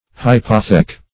Search Result for " hypothec" : The Collaborative International Dictionary of English v.0.48: Hypothec \Hy*poth"ec\, n. [F. hypoth[`e]que.